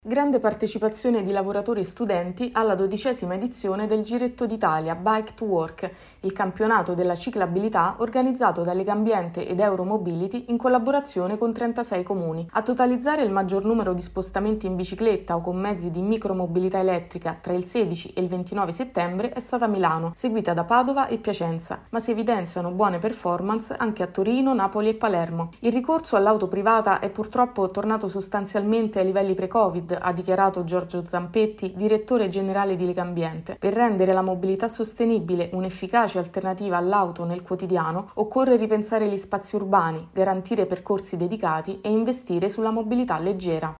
Presentato il Rapporto annuale di Associazione 21 luglio: in Italia meno famiglie rom in emergenza abitativa. Il servizio